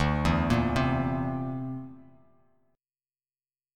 D7sus2#5 chord